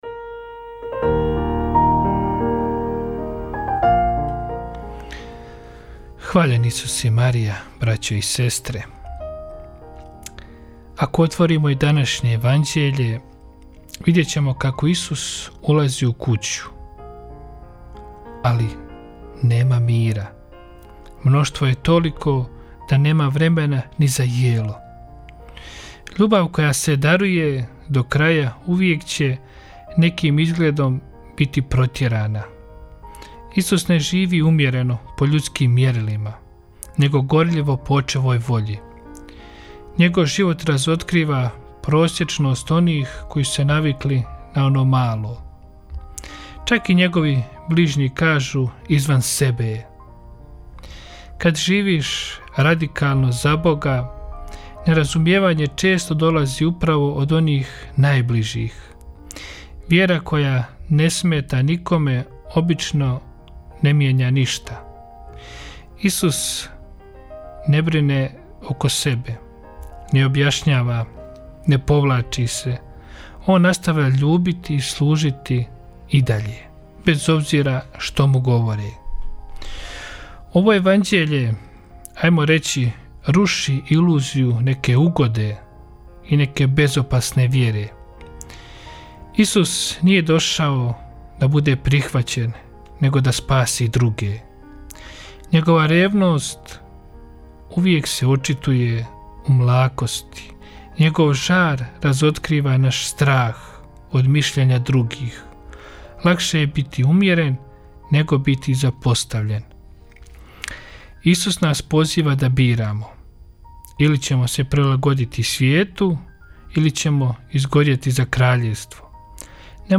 Emisije priređuju svećenici i časne sestre u tjednim ciklusima.